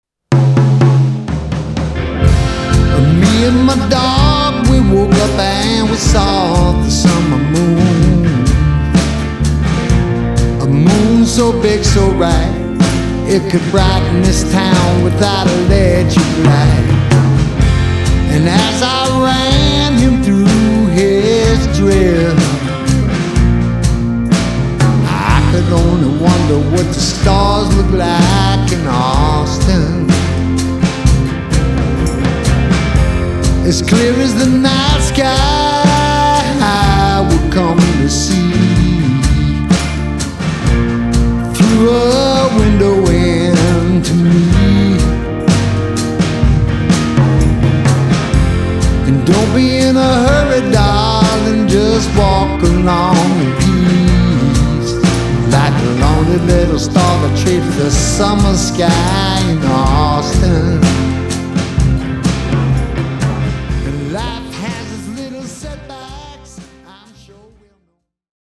guitars, bass, drums, vocals
organ